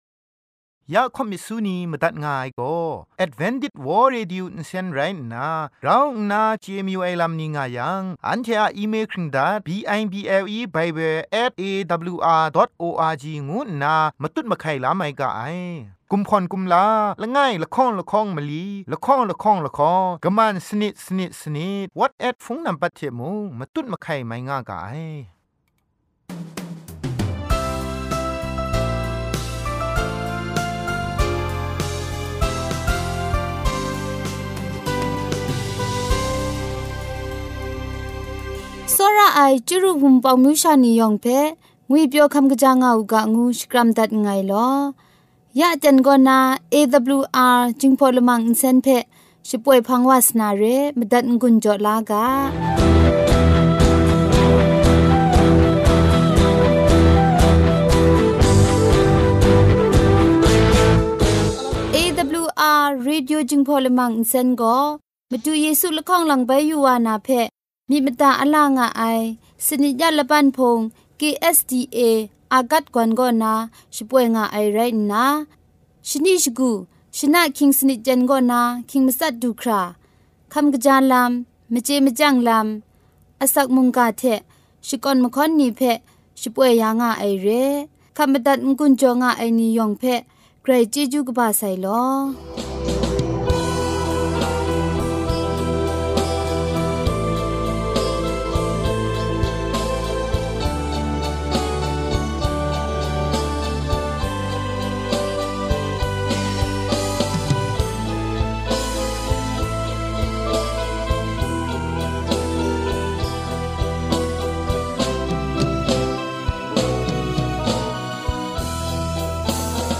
Gospel song,health talk,sermon.